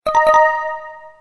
Звуки правильного ответа